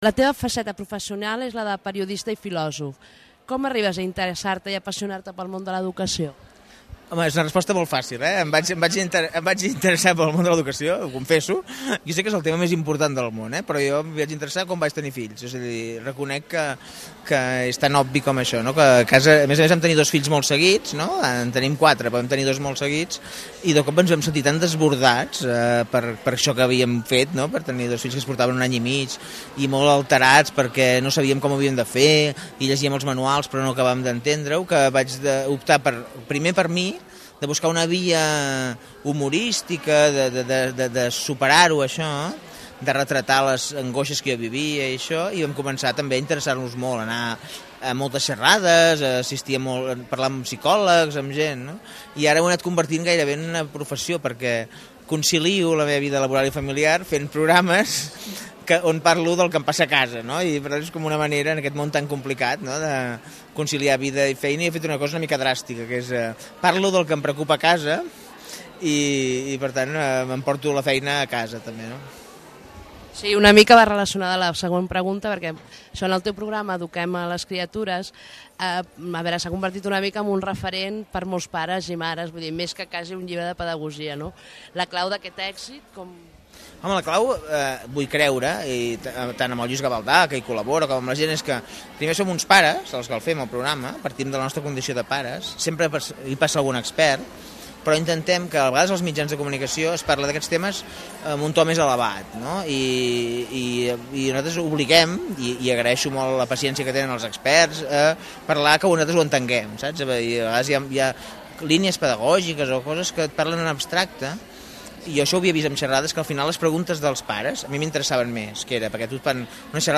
Entrevista Carles Capdevila.mp3